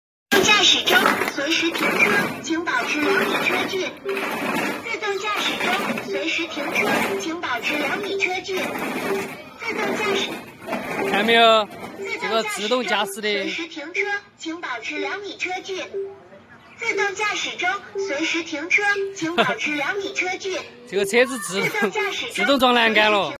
Self Driving car sound effects free download